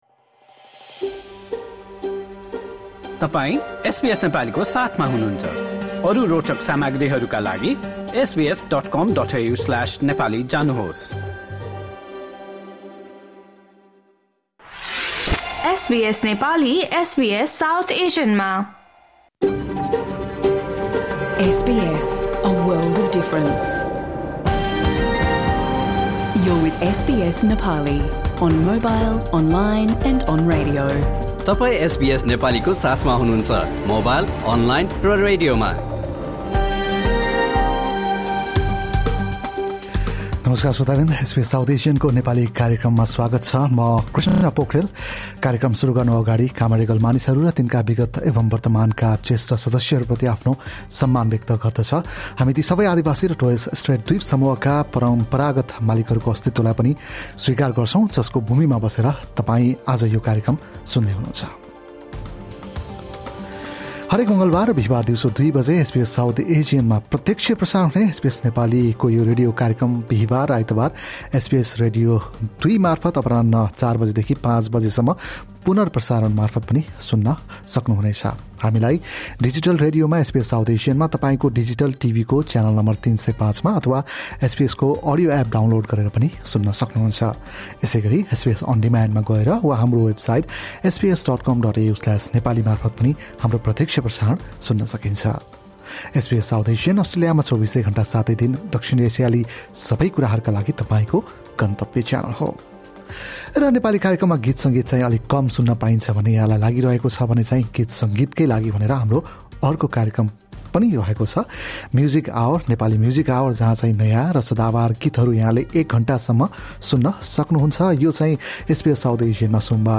SBS Nepali broadcasts a radio program every Tuesday and Thursday at 2 PM on SBS South Asian digital radio and channel 305 on your TV, live from our studios in Sydney and Melbourne.